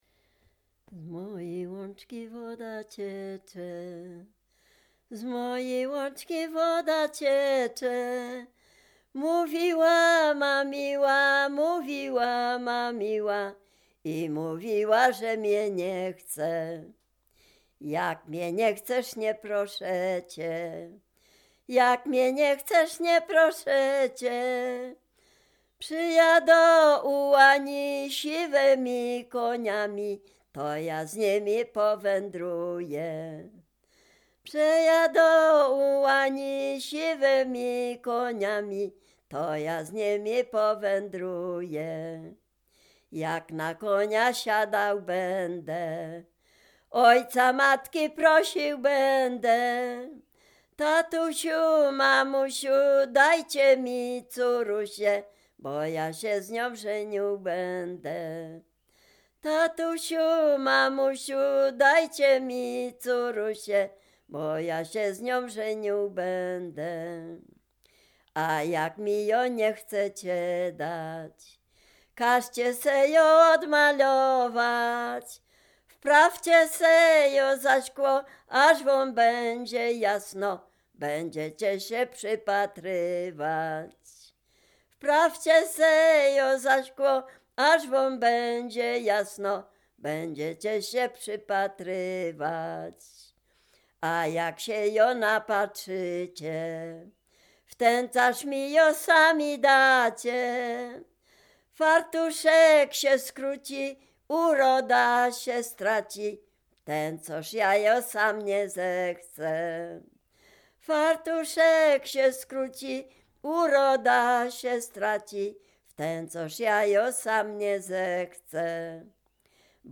Dolny Śląsk, powiat bolesławiecki, gmina Nowogrodziec, wieś Zebrzydowa
liryczne miłosne rekruckie